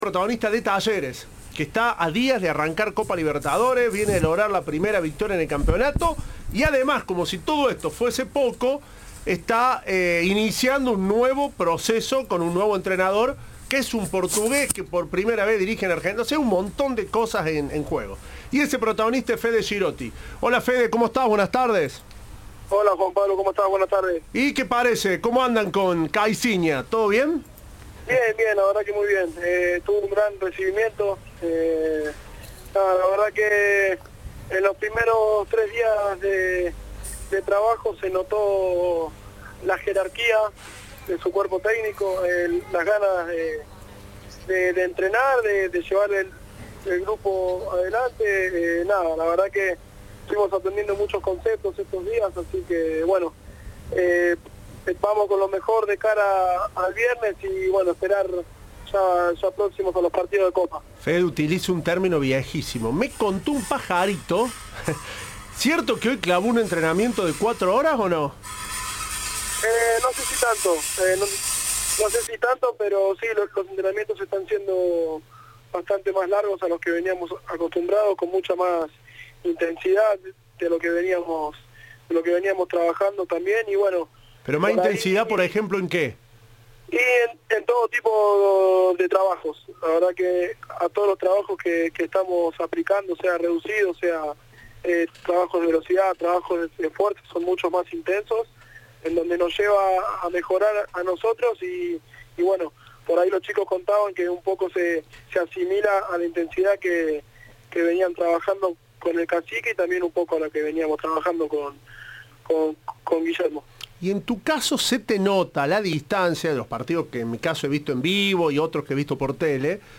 Entrevista de "Tiempo de Juego"